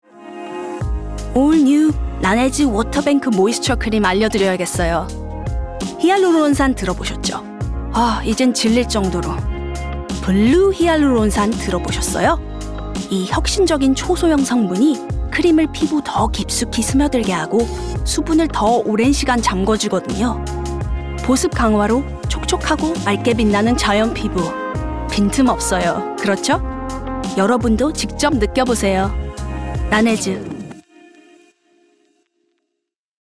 Accent: Korean Characteristics: Friendly and Conversational Age: 20-30 Age: 30-40 View on spotlight Korean Commercial